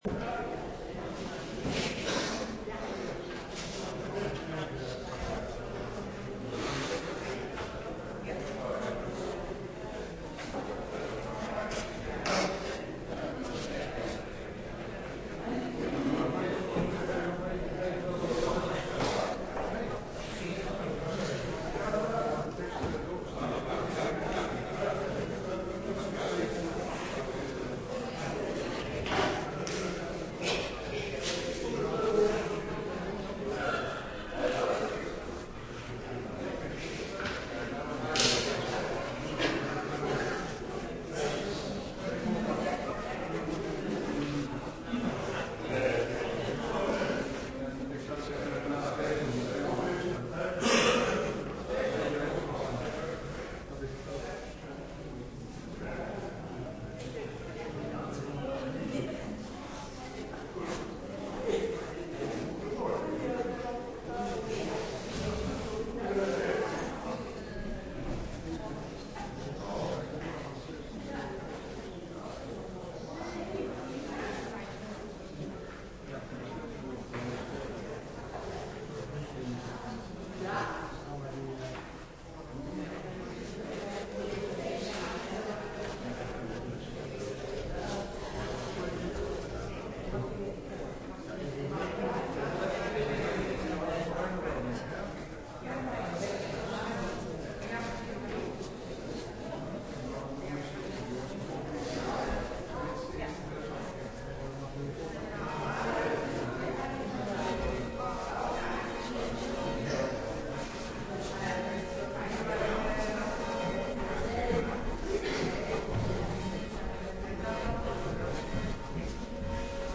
Preek over Glas 28 op zondagmiddag 16 februari 2025 (Goudse Glazendienst) - Pauluskerk Gouda